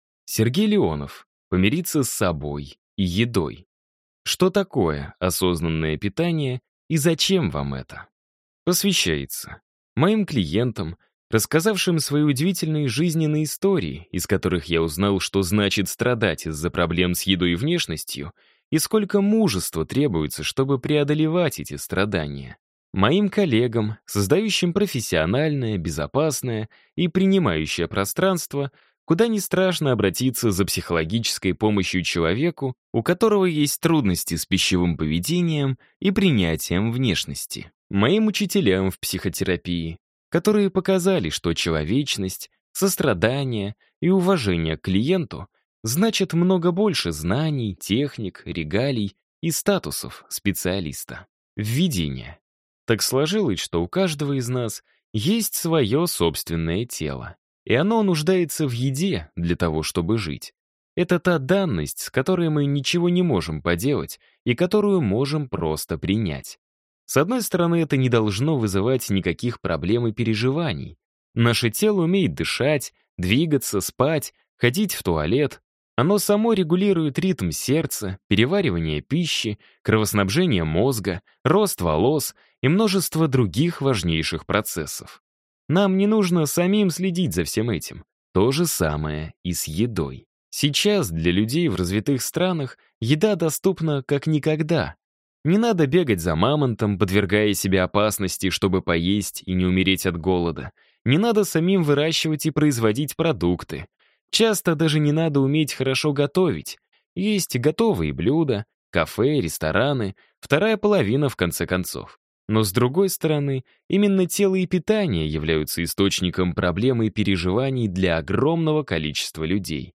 Аудиокнига Помириться с собой и едой. Что такое осознанное питание и зачем вам это?